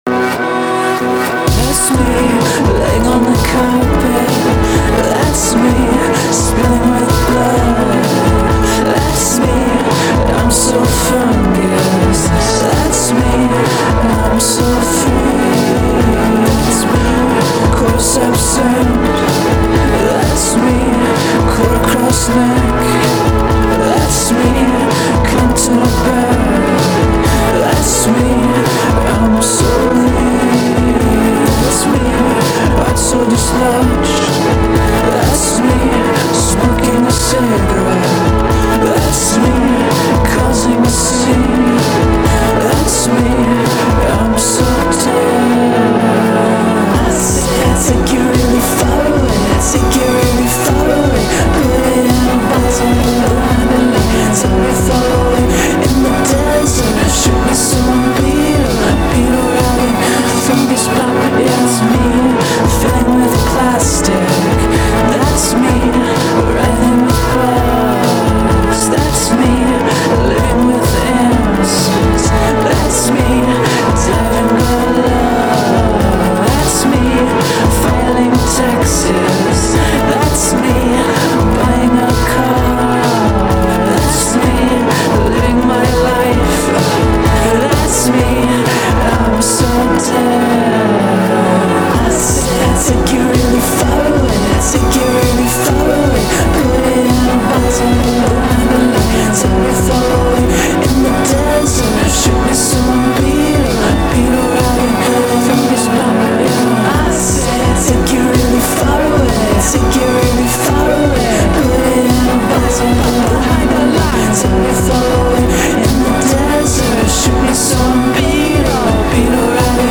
Hip Hop
Singer and songwriter